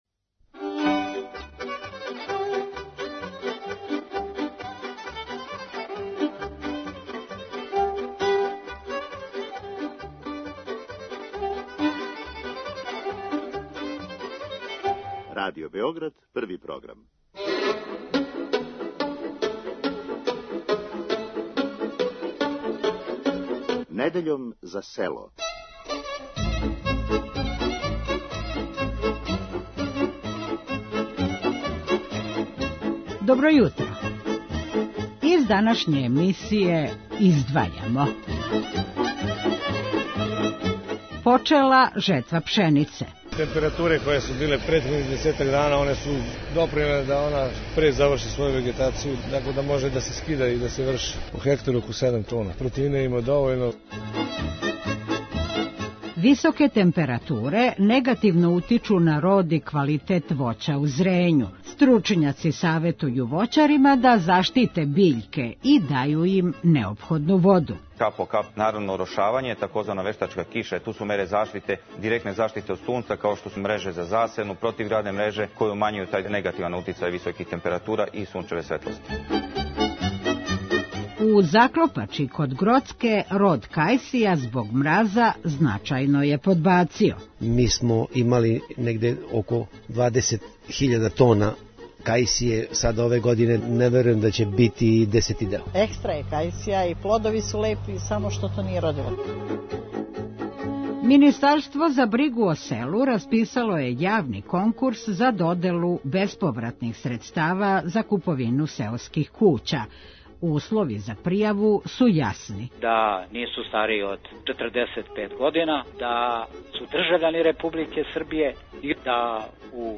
Протекле недеље посетили смо Заклопачу, село које је познато о производњи трешања и кајсија.